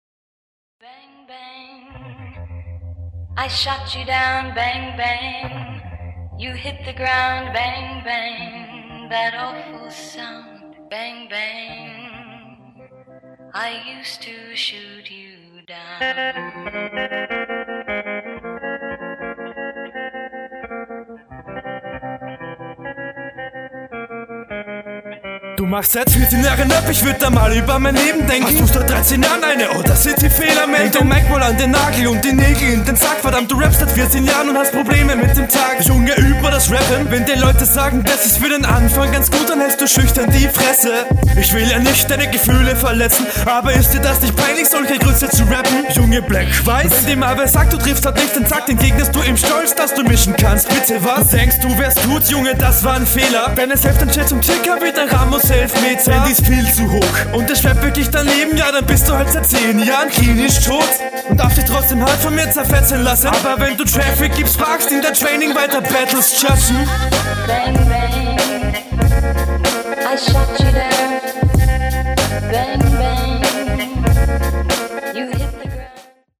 Ich hasse dieses Sample :D Einstieg is leicht zu früh, da dreht sich mir sofort …
Schön Druckvoller Einstieg!